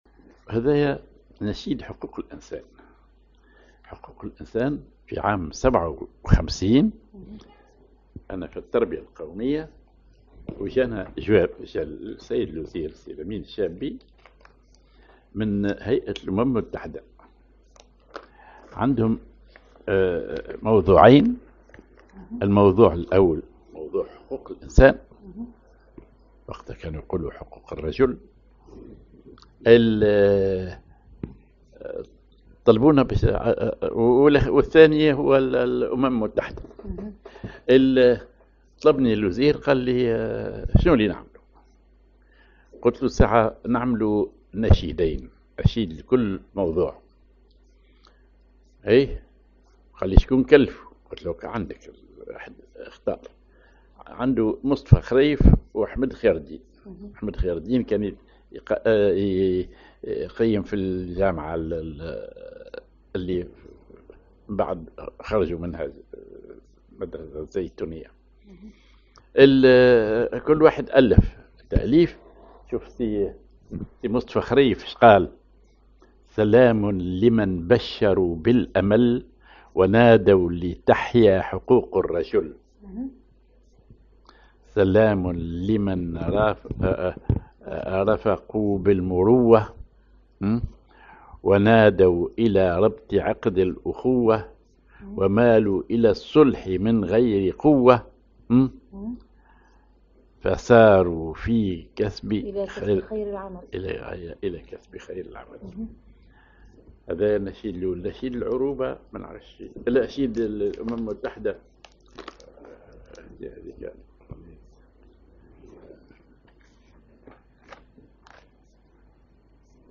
ماجور على الجهاركاه
genre نشيد